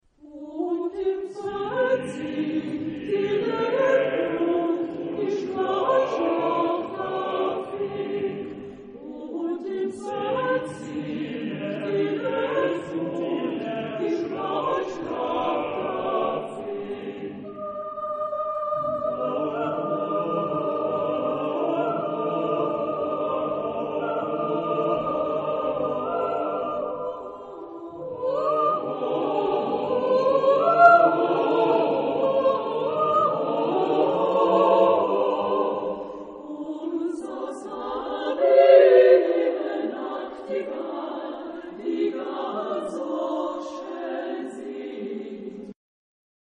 Genre-Style-Forme : Chanson ; Folklore ; Profane
Caractère de la pièce : joyeux ; vivant ; entraînant ; coloré
Type de choeur : SSAATTBB  (8 voix mixtes )
Tonalité : sol majeur